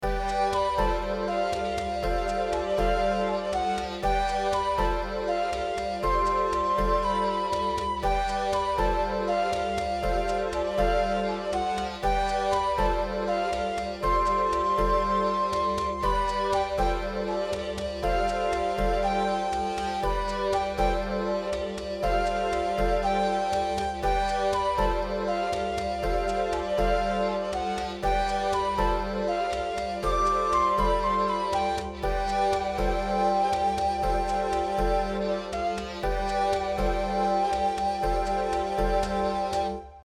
Loop Full Score